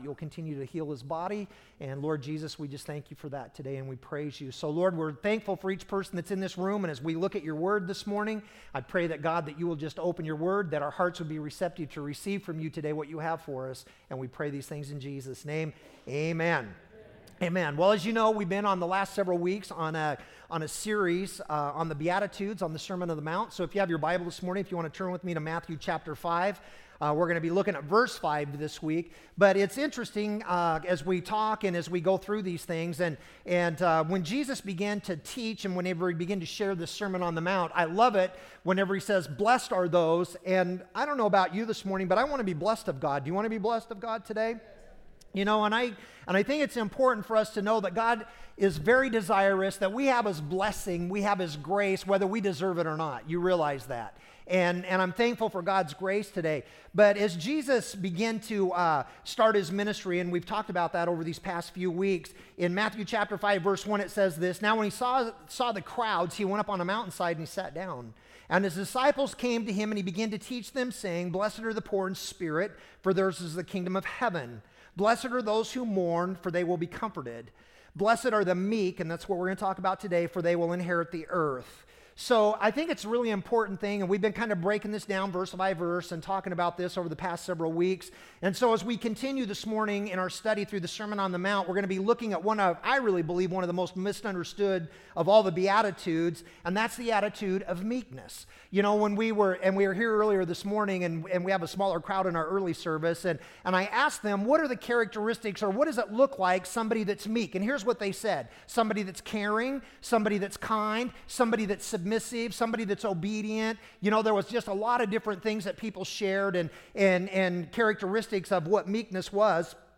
Sermons - Redmond Assembly of God